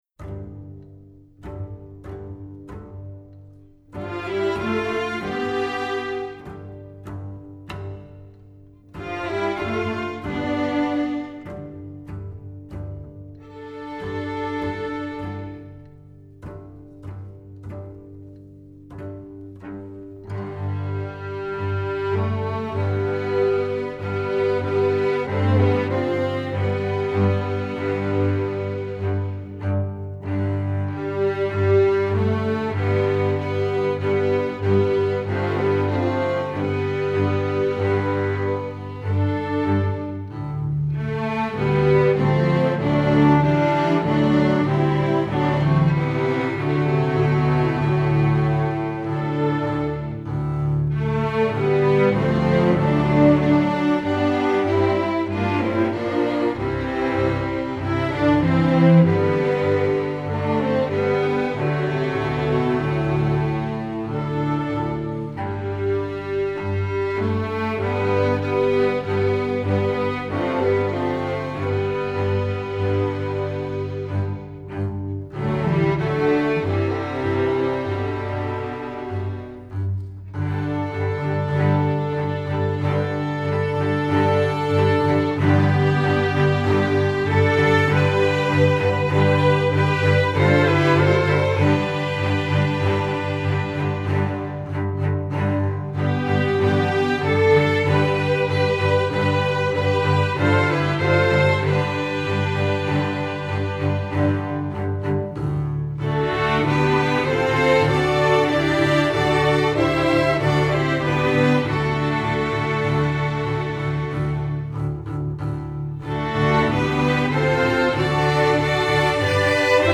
Category: String Orchestra